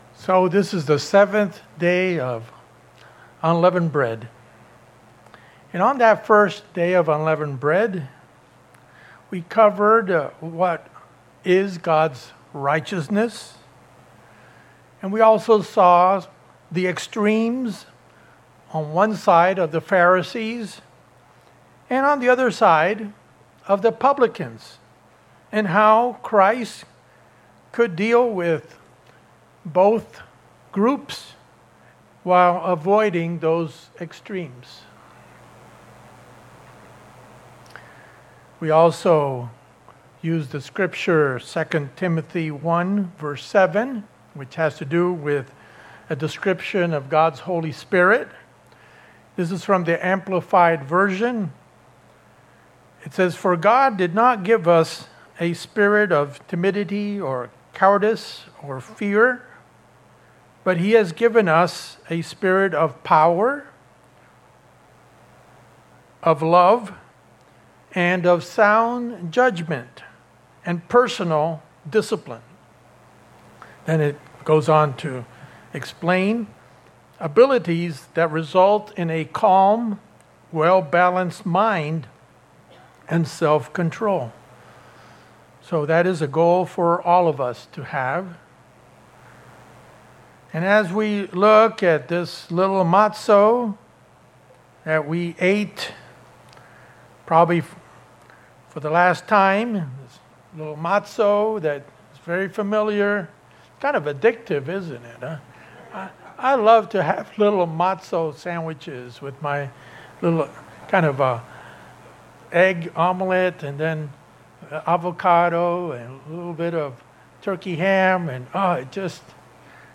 In this Part 2 message of developing a godly balance of righteousness, we are exhorted to always be striving for saintly perfection with humility. Here is a review of Bible scriptures that give us the step-by step process toward conversion and achieving a balance in righteousness.